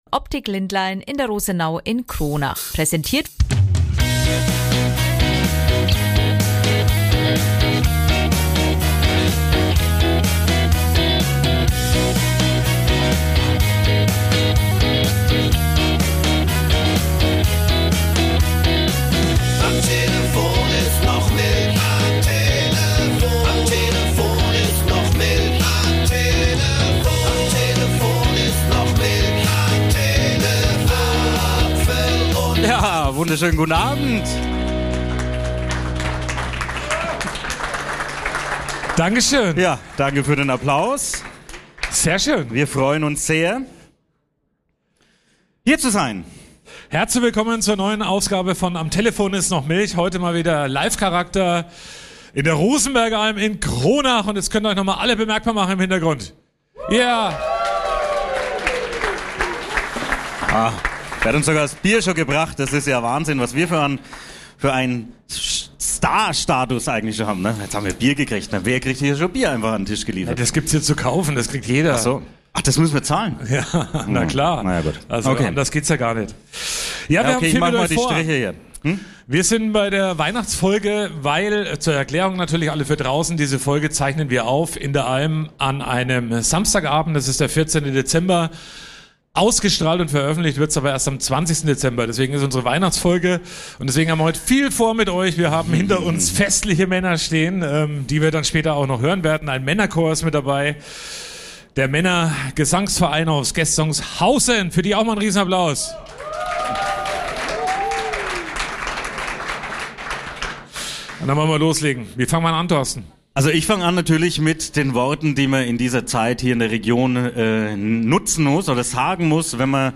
#145: Die Weihnachtsfolge - Das Live-Event in der Rosenbergalm in Kronach ~ Am Telefon ist noch Milch Podcast
Die Themen in dieser Ausgabe: - Bratwürste im Lebkuchen - Herrliche Musik vom Männergesangverein Gestungshausen - Top-Getränke in der Alm - Lohnt sich die Schauspielerei?